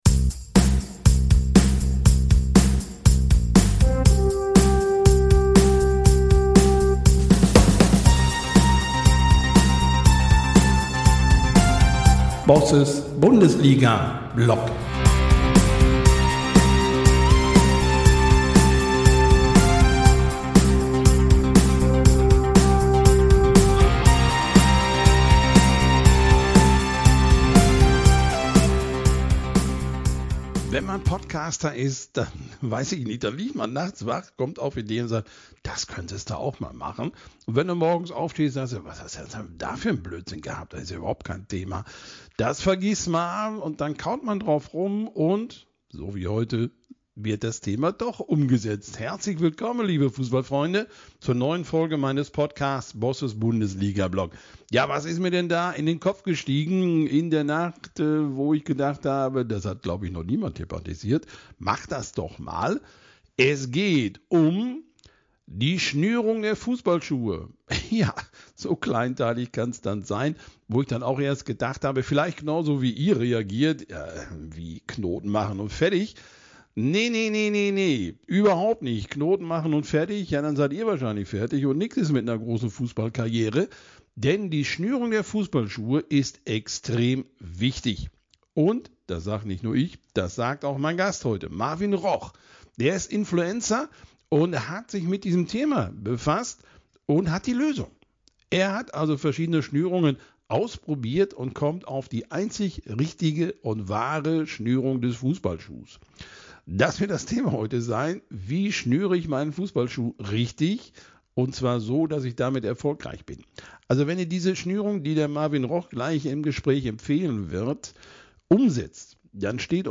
Hier ist die 23. Ausgabe von welle1953, der Radioshow auf coloRadioDresden über die SGD, die ihr natürlich auch als Podcast abonnieren ...